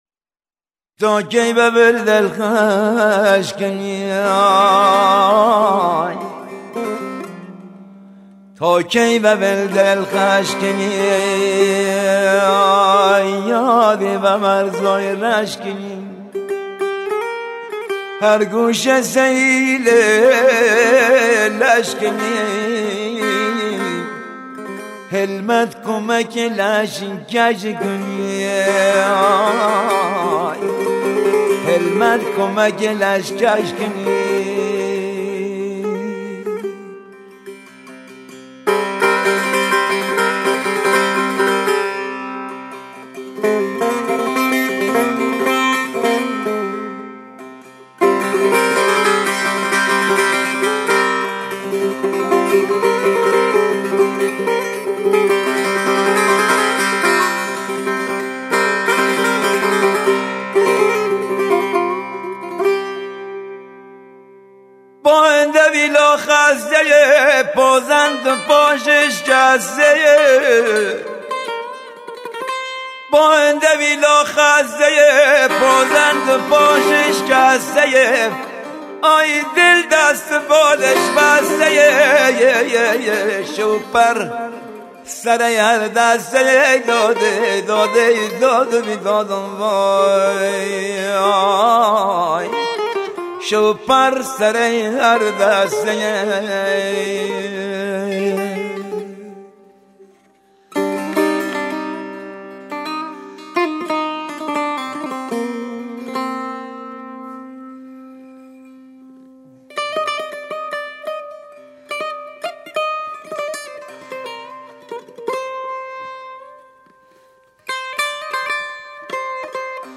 آهنگ بختیاری
فوق العاده احساسی